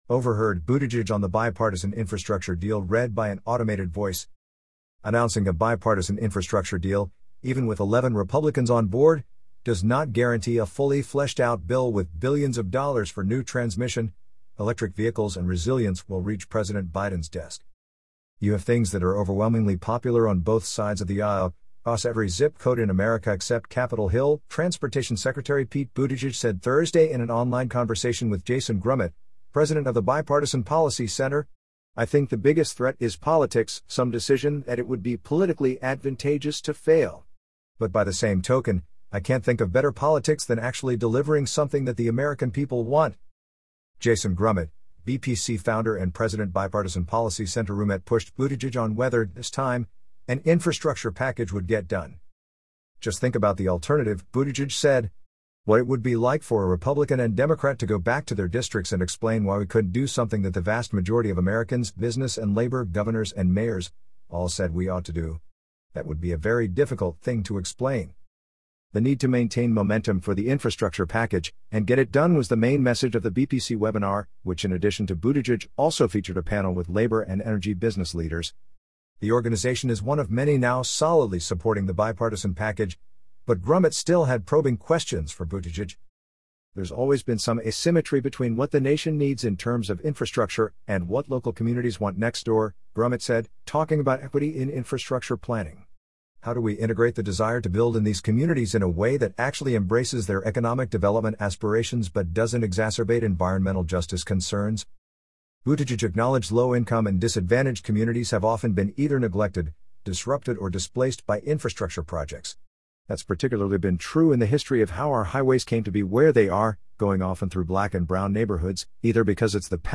Transportation Secretary Pete Buttigieg spoke online